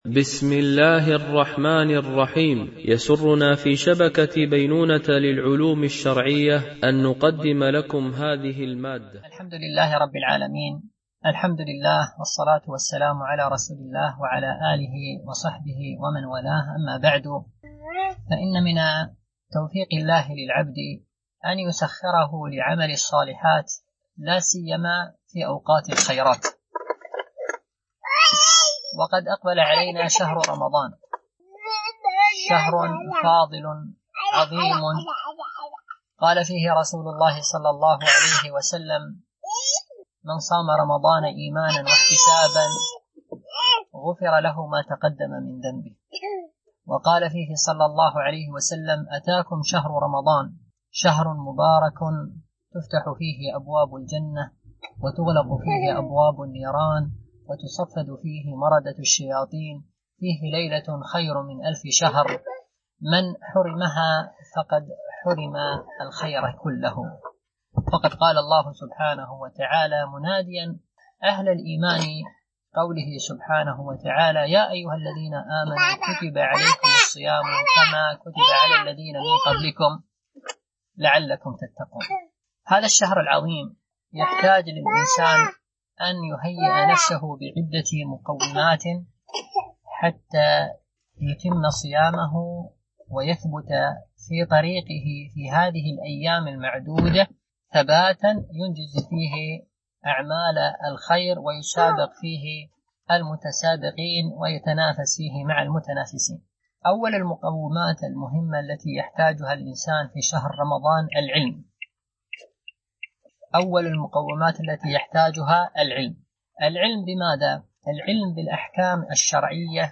محاضرات
التنسيق: MP3 Mono 22kHz 32Kbps (CBR)